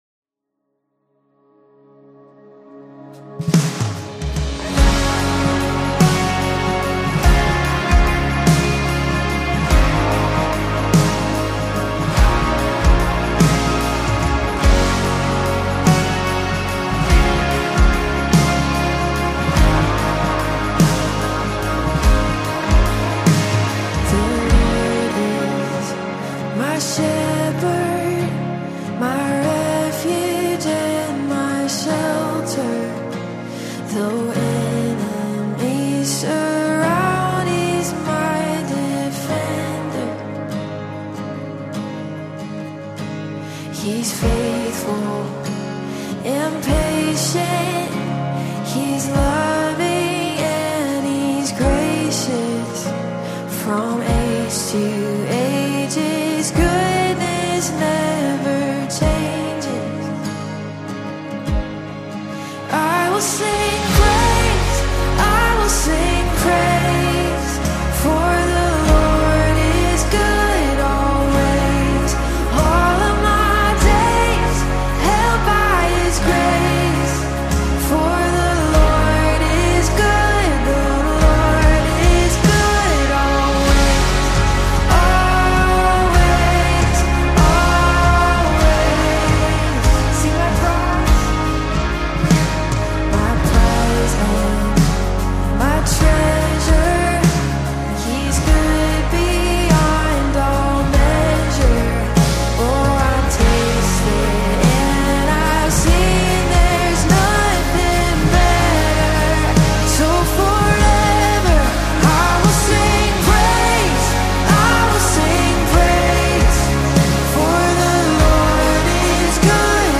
is a powerful gospel expression of constant devotion